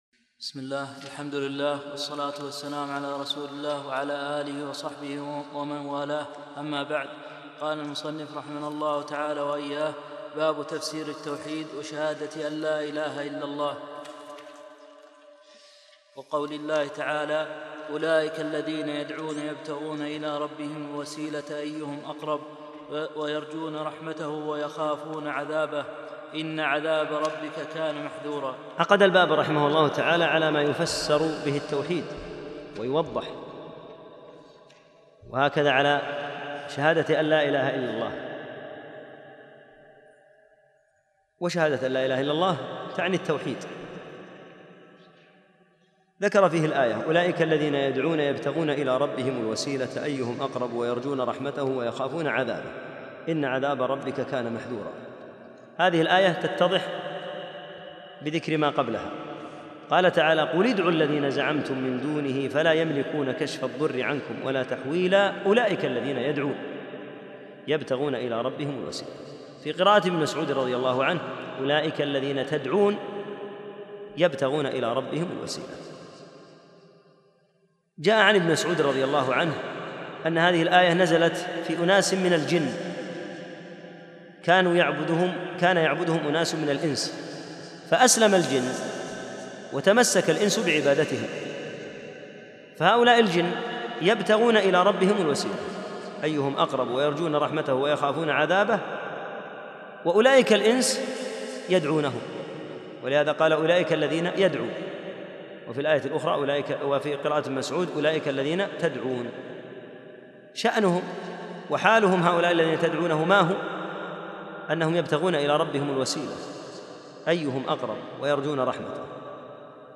6 - الدرس السادس الدروس 4-كتاب التوحيد